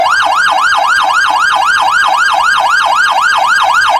Emergency Siren
A wailing emergency siren cycling through high and low tones with urban reverb
emergency-siren.mp3